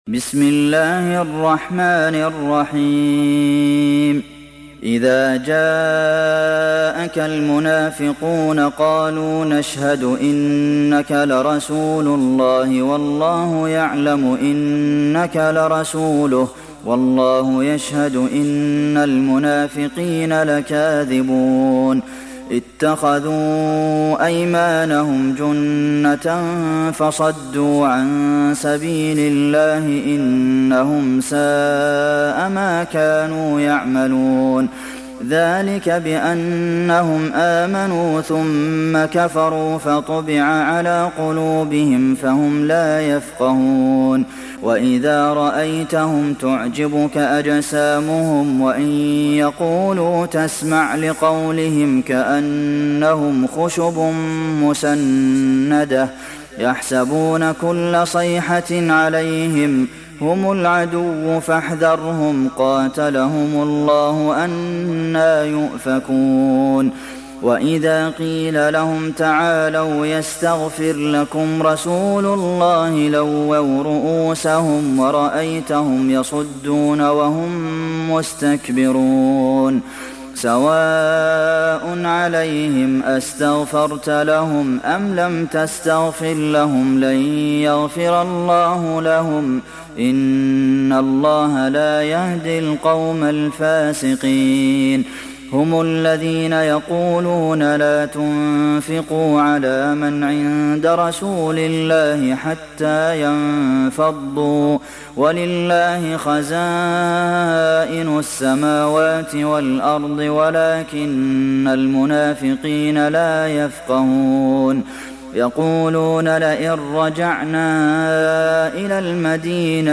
Sourate Al Munafiqun Télécharger mp3 Abdulmohsen Al Qasim Riwayat Hafs an Assim, Téléchargez le Coran et écoutez les liens directs complets mp3